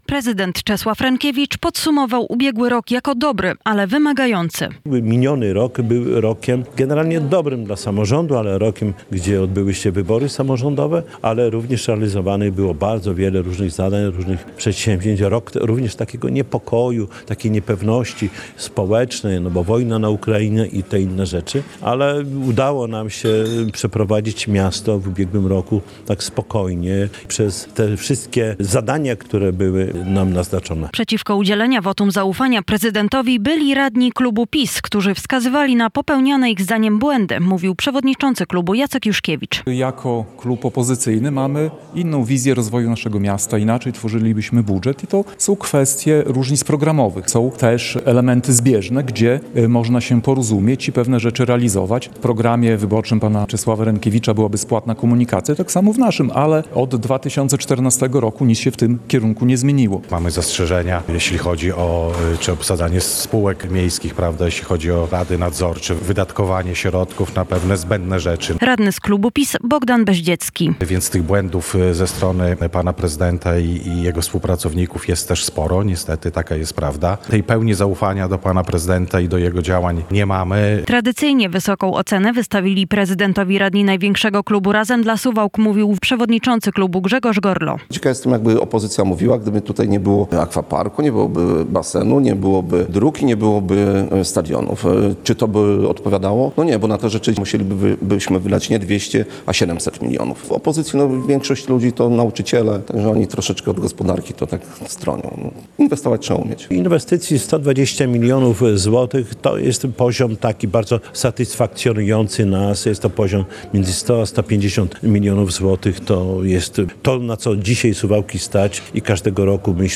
Suwalscy radni udzielili wotum zaufania i absolutorium prezydentowi miasta - relacja